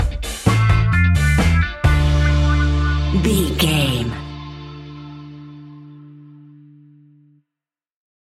Classic reggae music with that skank bounce reggae feeling.
Aeolian/Minor
D
laid back
chilled
off beat
drums
skank guitar
hammond organ
percussion
horns